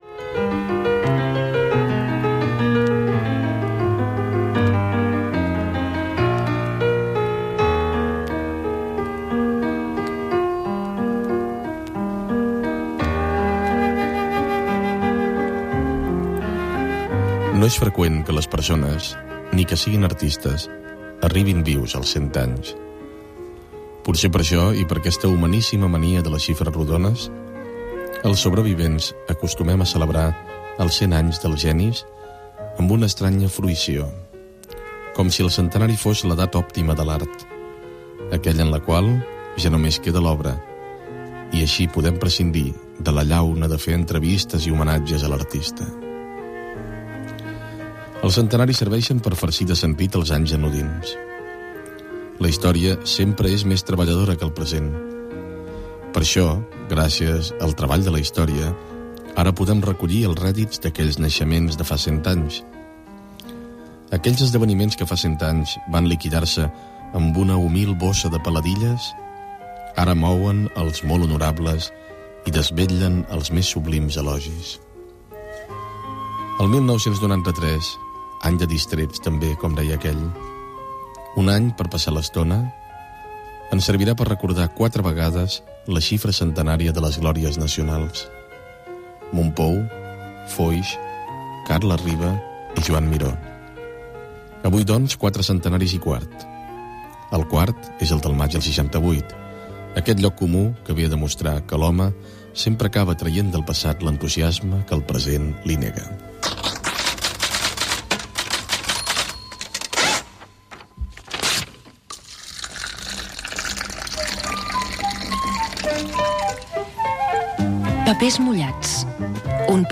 Reflexió inicial sobre els centeraris de quatre catalans il·lustres, careta del programa, escrit de J.V.Foix, tema musical